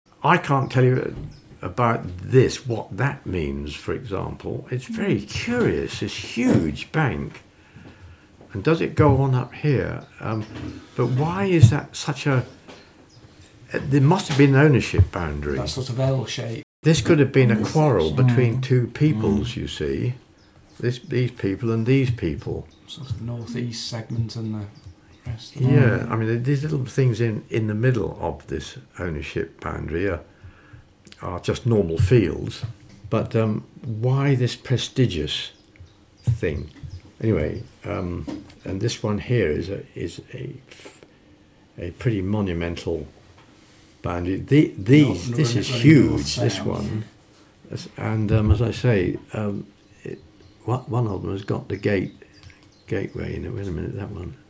Oral history reveals fascinating story of Kingley Vale